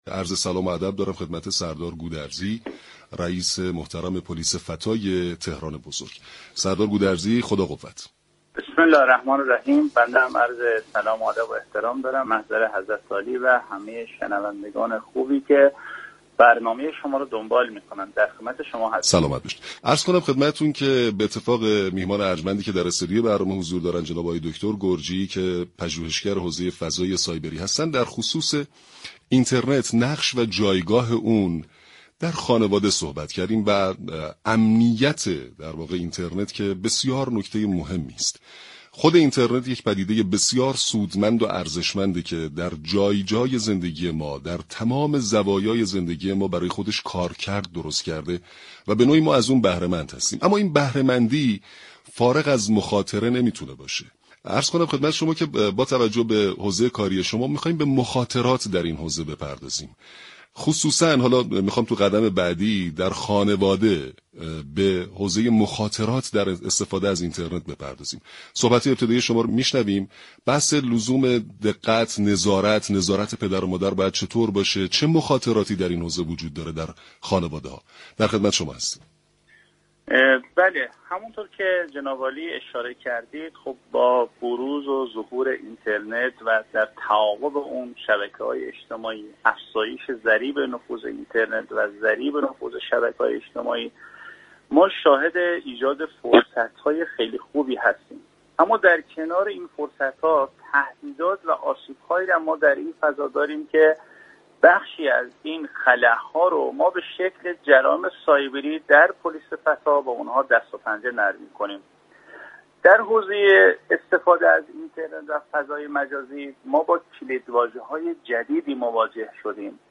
رئیس پلیس فتای تهران بزرگ در گفت و گو با رادیو تهران با تاكید بر اینكه نزدیكی والدین به فرزندان و افزایش اطلاعات سایبری آنها می‌تواند فرزندانشان را در مقابل جرایم سایبری حفظ كند گفت: در بازی‌های رایانه‌ای (آنلاین) فرزندان ما با افرادی بزرگتر از خود روبرو هستند.